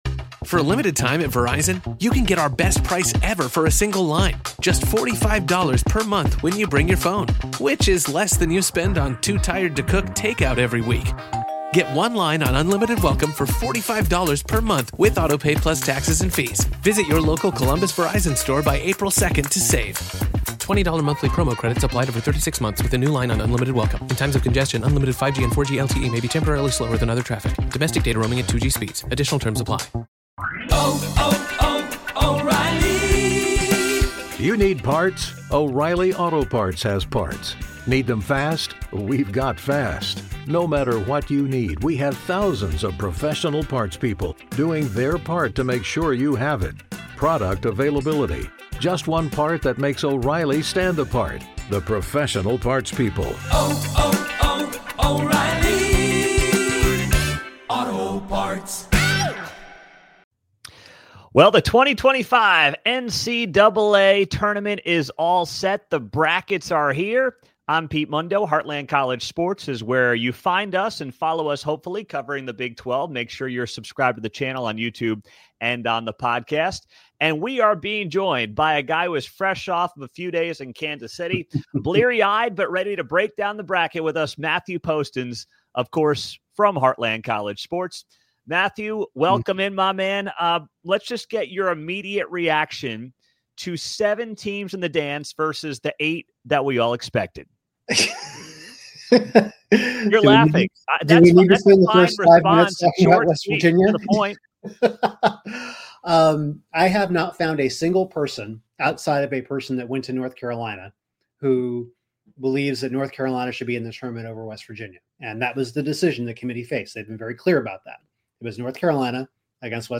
1 The Great Crime Against America's Cities: Government Mismanagement (Guest: Miami Mayor Francis Suarez) 34:16